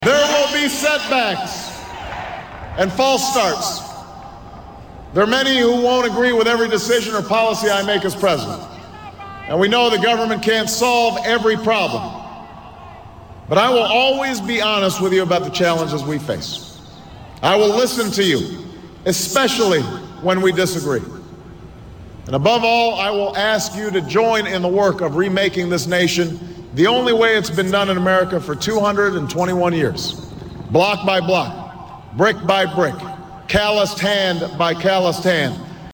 Tags: Barack Obama Barack Obama speech Barack Obama clips US President History